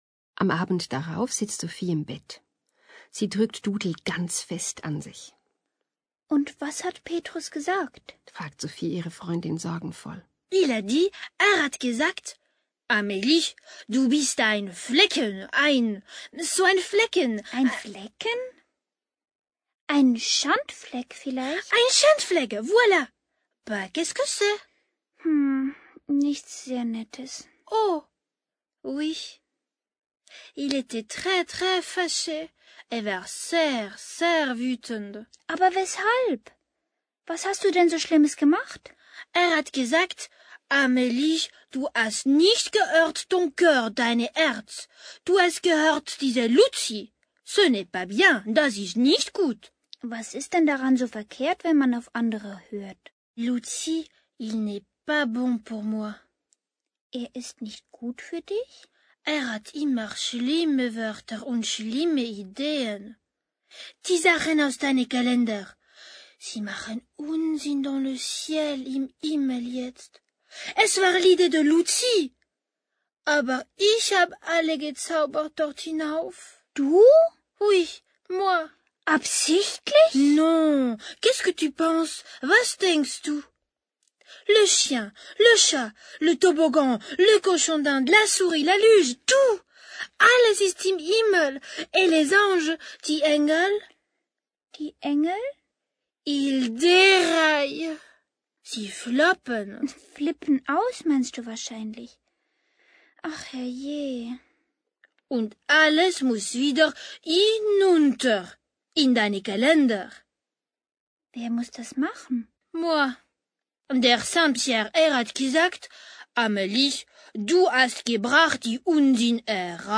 Ganz viel umgangssprachliches Französisch locker, flockig präsentiert.
«Schusselengel Amélie» ist ein vorweihnachtliches Hörspiel für Kinder, die gerne Französisch lernen möchten und für solche, die Französisch nicht ausstehen können, erst recht.
deutsch – französisch, verständlich auch ohne Französischkenntnisse